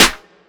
THE SACRED SNARE.wav